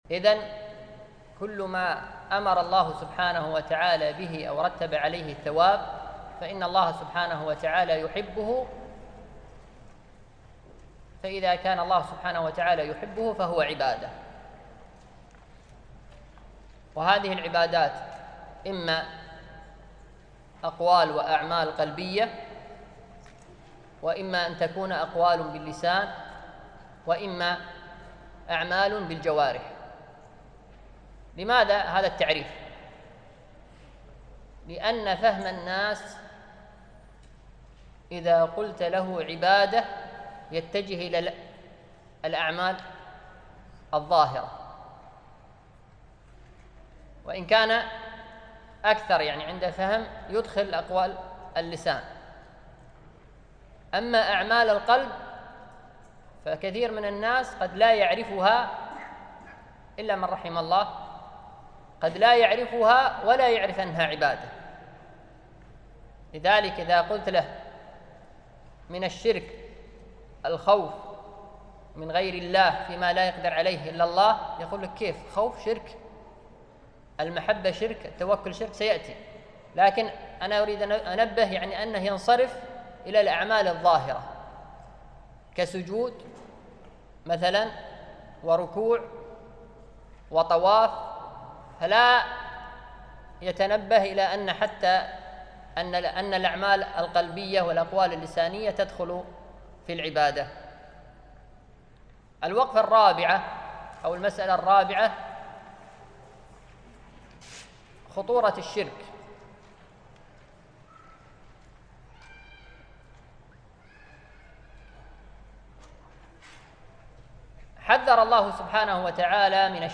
الدرس الأول - الجزء الثاني